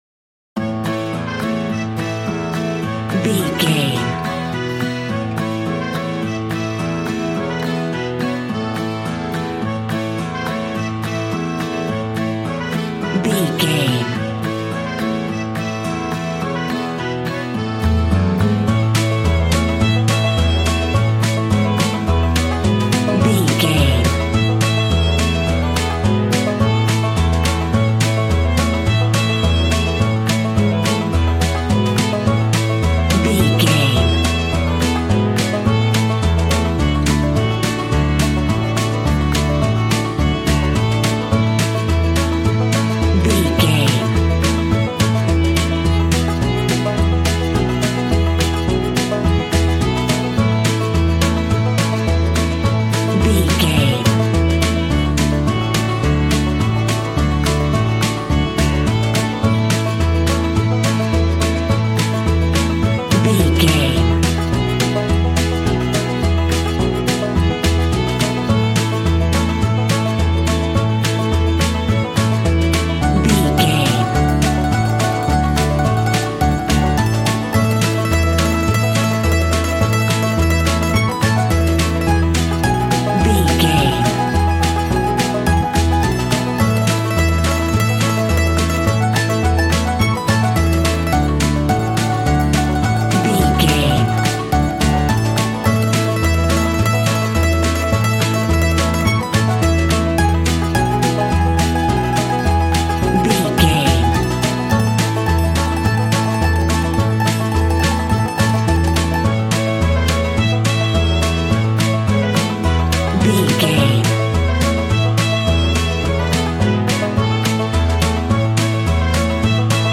Ionian/Major
D
Fast
fun
bouncy
positive
double bass
drums
acoustic guitar